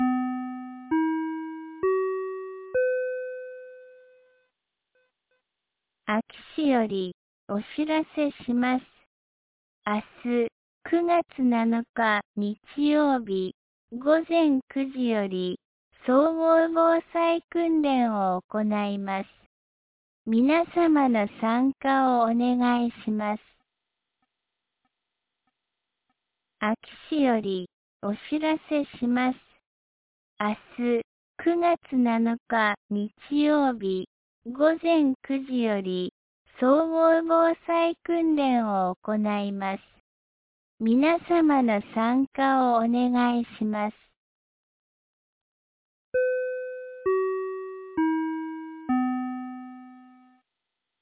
2025年09月06日 17時20分に、安芸市より全地区へ放送がありました。